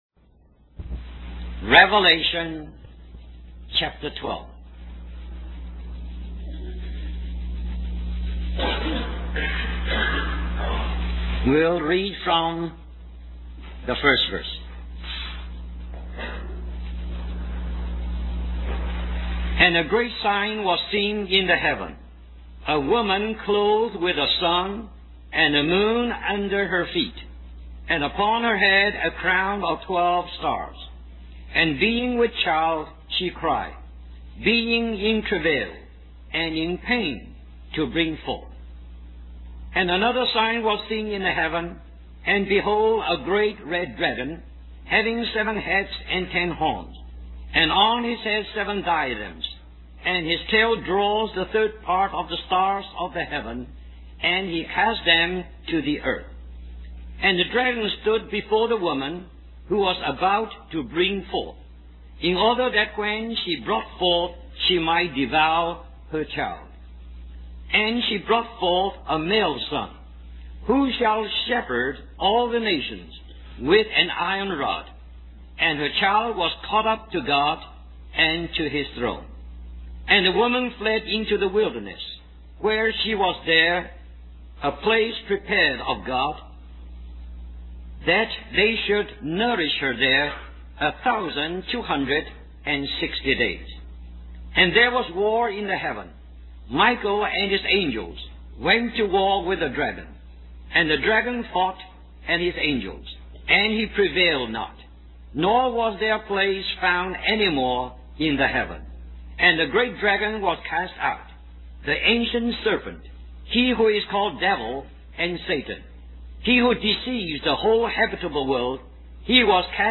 A collection of Christ focused messages published by the Christian Testimony Ministry in Richmond, VA.
Christian Family Conference